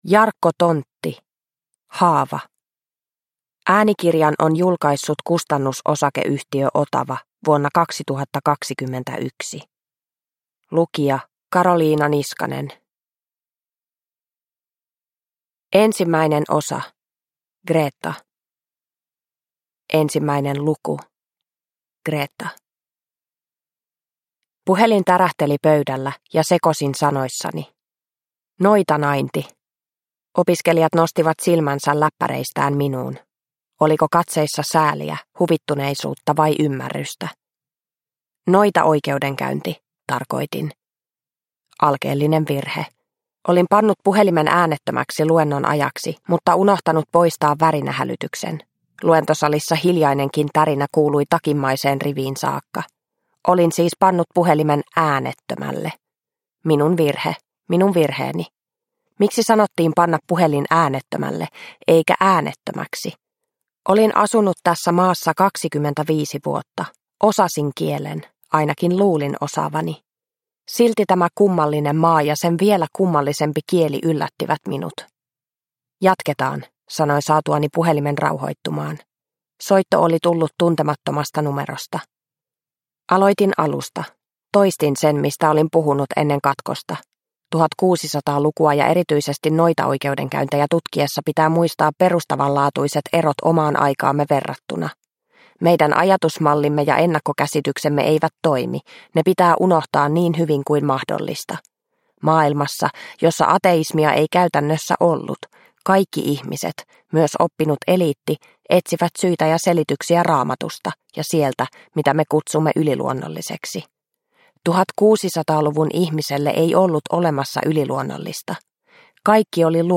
Haava – Ljudbok – Laddas ner